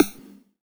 SNARE 44  -L.wav